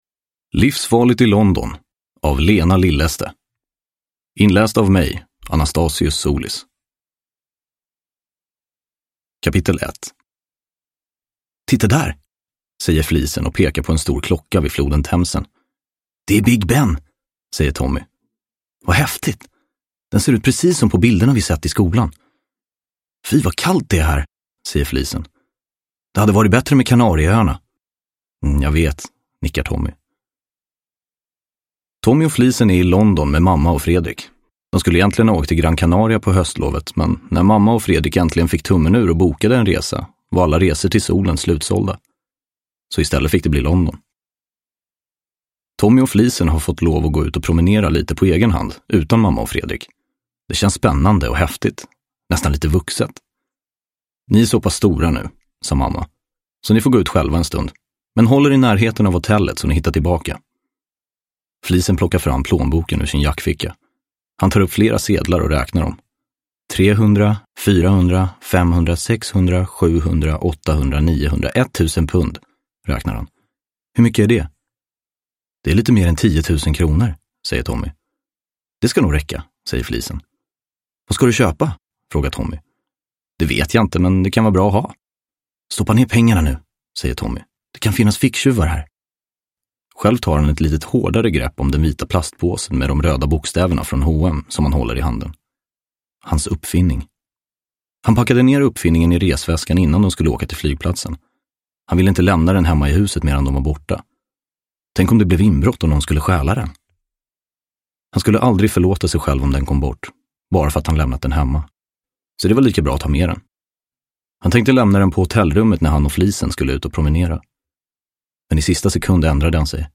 Livsfarligt i London – Ljudbok – Laddas ner